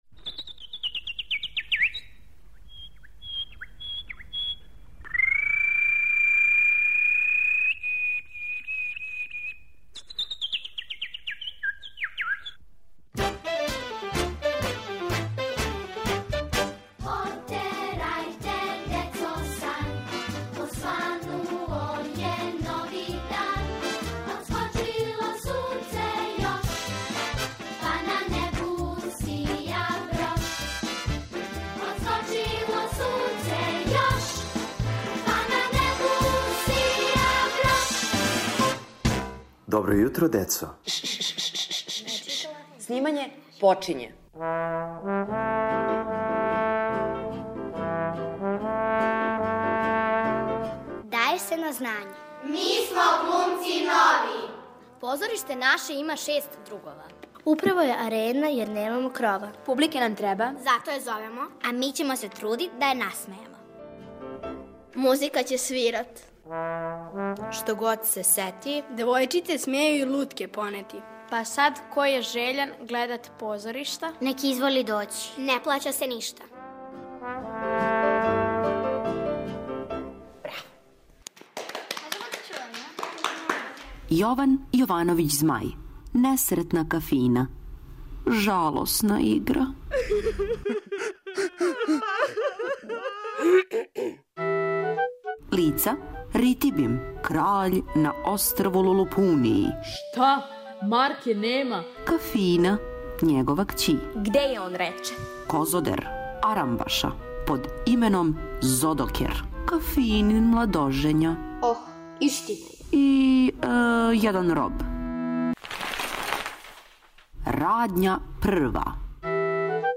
Слушате прву епизоду радио драме "Несретна Кафина", по тексту Јована Јовановића Змаја.